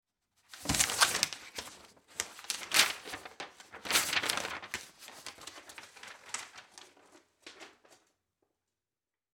Звуки шуршания бумагой
Человек поднял в руку лист бумаги